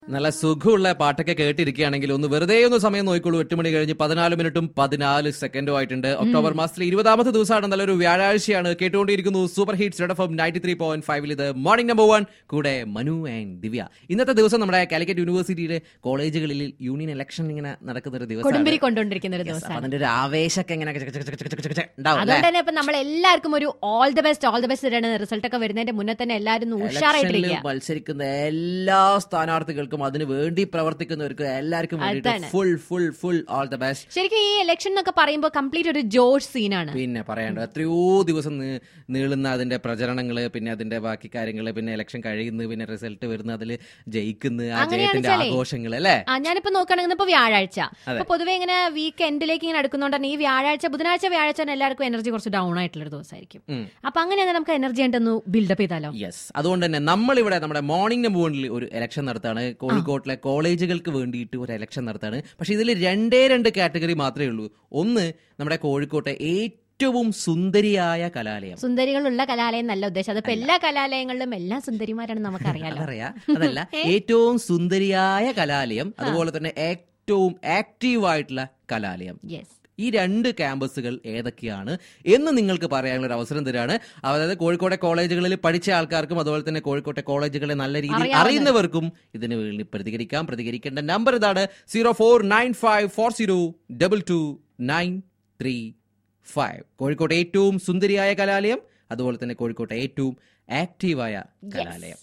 ELECTION IN RED FM MORNING SHOW TO SELECT THE MOST BEAUTIFUL AND MOST ACTIVE COLLEGE CAMPUS IN CALICUT.